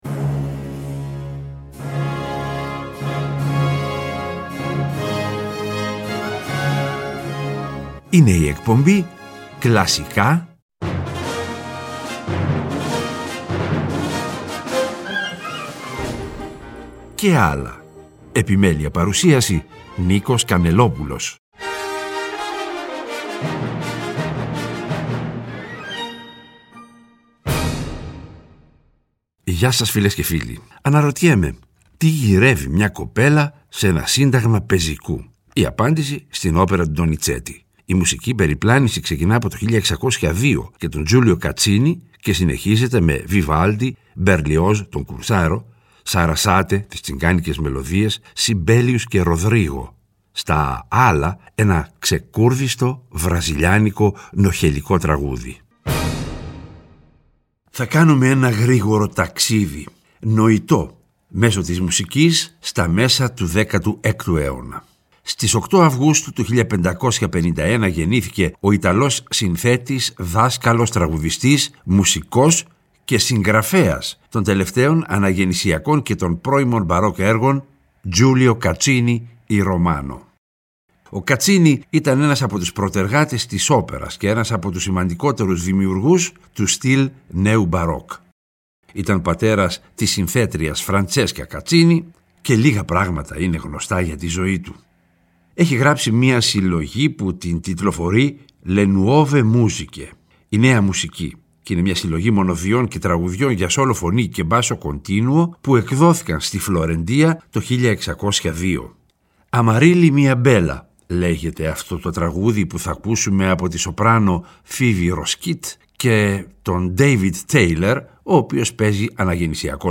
Στα «άλλα», ένα… ξεκούρδιστο, νωχελικό, βραζιλιάνικο τραγούδι.